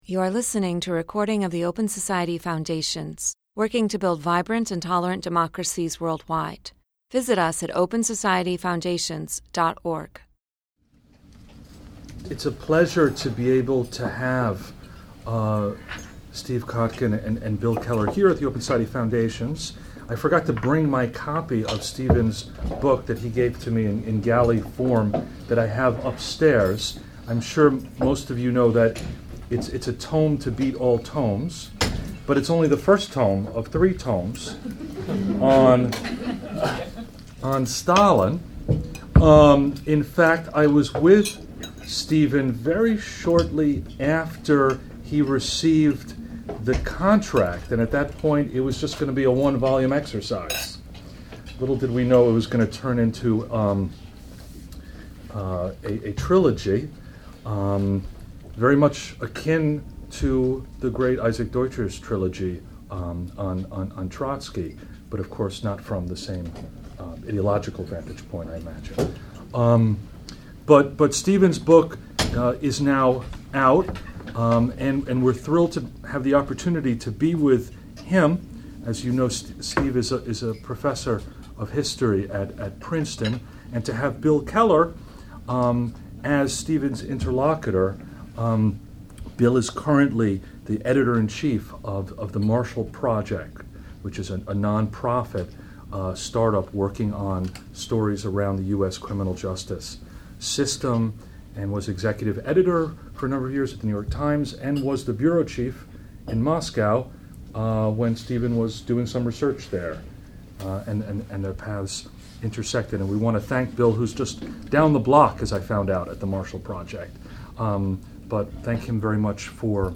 Stephen Kotkin and Bill Keller discuss Stalin’s history and how its reading could shed light on the public and political life of Putin’s Russia.